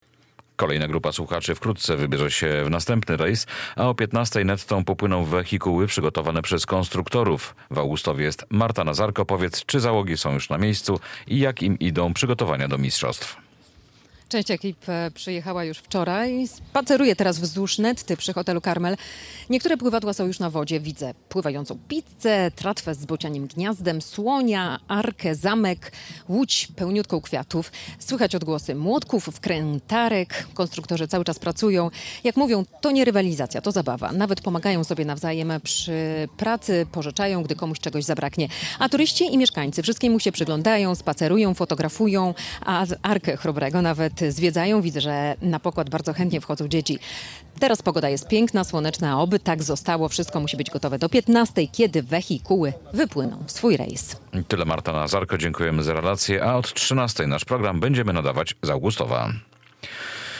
Pływadła prawie gotowe, uczestnicy dokonują ostatnich poprawek - relacja